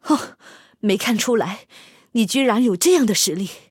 SU-122A中破语音.OGG